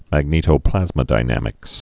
(măg-nētō-plăzmə-dī-nămĭks)